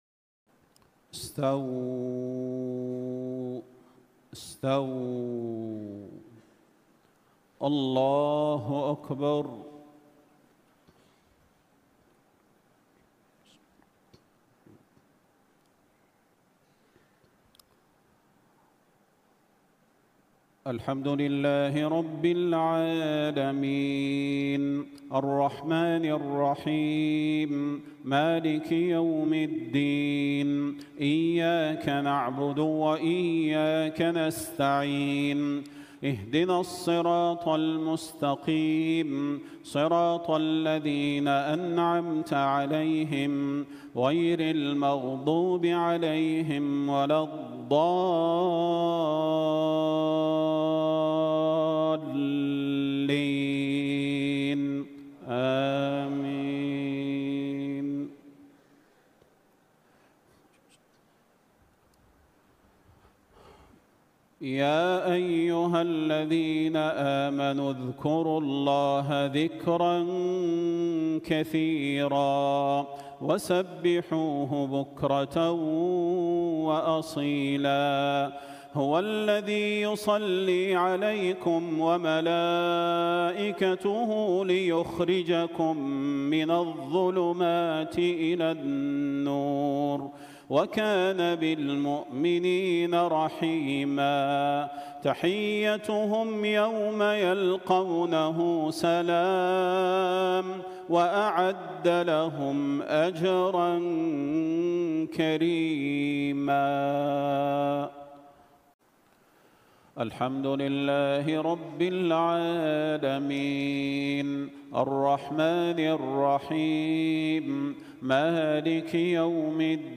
صلاة الجمعة 27 شوال 1446هـ بمسجد السلطان محمد الأعظم بجمهورية المالديف > تلاوات و جهود الشيخ صلاح البدير > تلاوات وجهود أئمة الحرم النبوي خارج الحرم > المزيد - تلاوات الحرمين